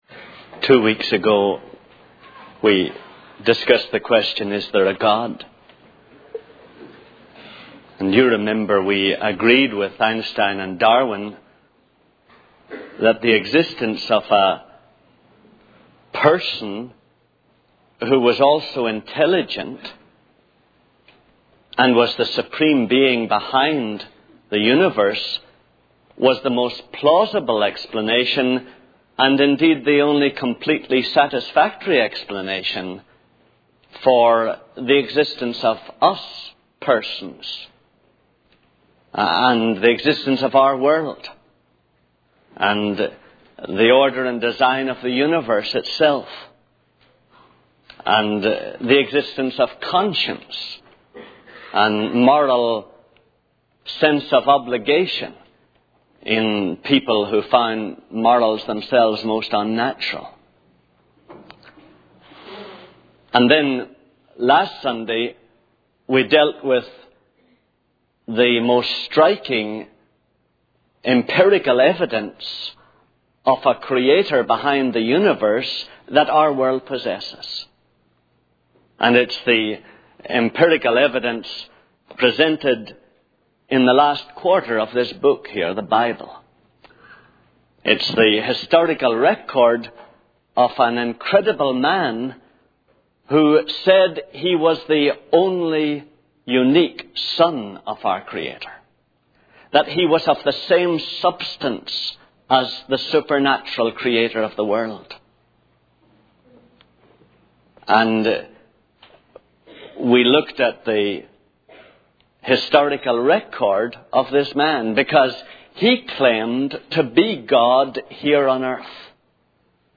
In this sermon, the speaker discusses the existence of God and presents Einstein and Darwin's views as evidence for a supreme being behind the universe. The sermon then focuses on the historical record of Jesus Christ as presented in the Bible. The speaker emphasizes the reliability of the Bible and highlights Jesus' claims to be the unique son of God and the creator of the world.